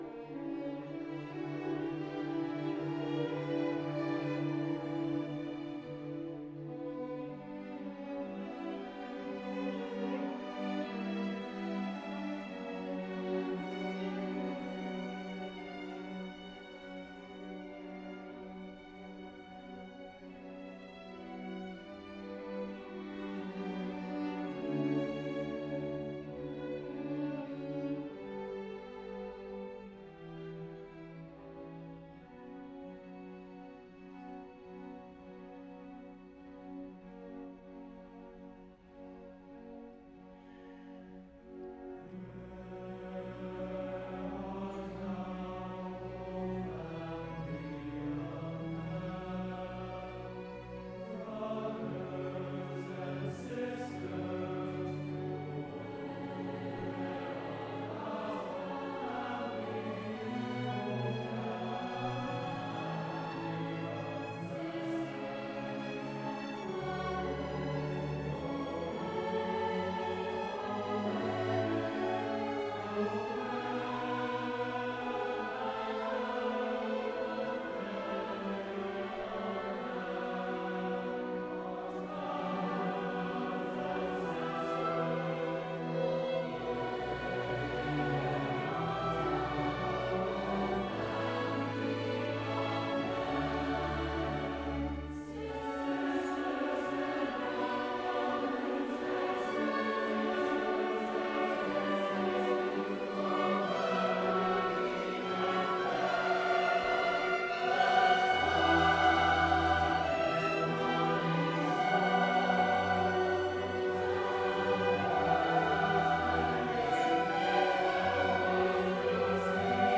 Spring concert
St. John the Divine